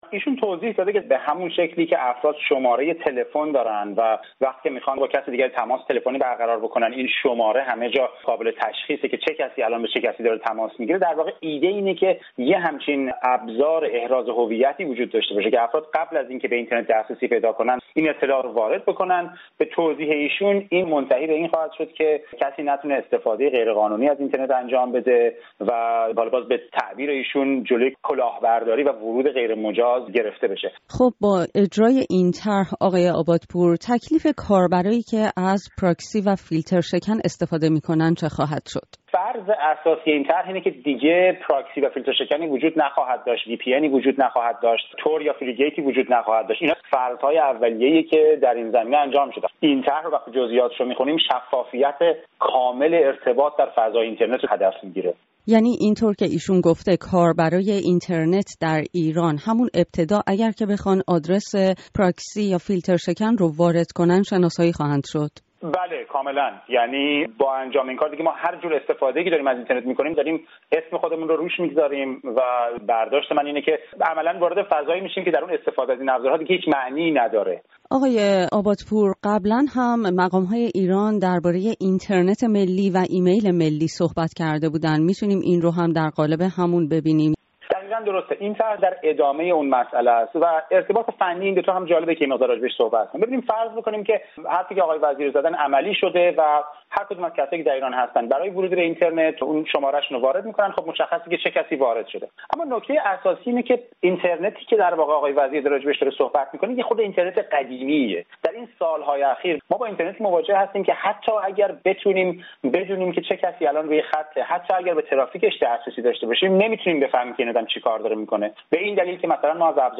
گفت وگوی